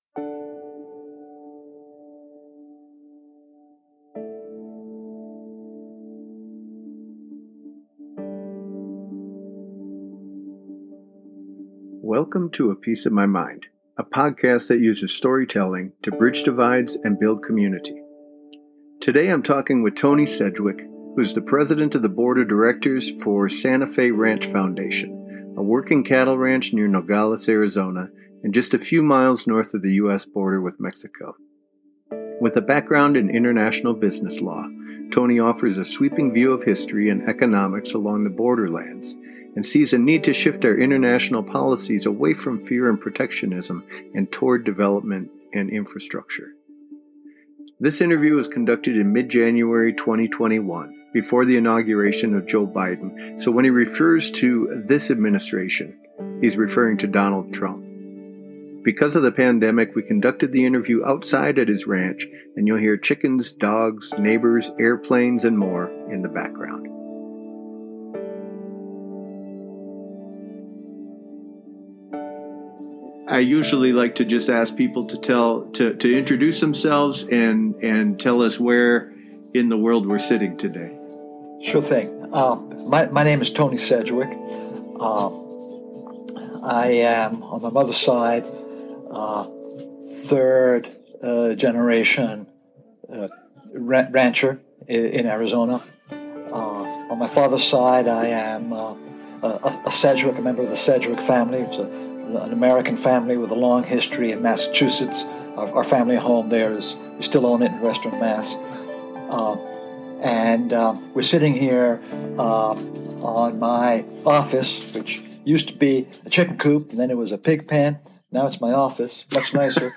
This interview was conducted in mid-January 2021, before the inauguration of Joe Biden, so when he refers to “this administration” he is referring to Donald Trump. Because of the pandemic, we conducted the interview outside at his ranch, so in the audio, you’ll hear chickens, dogs, neighbors, airplanes and more in the background.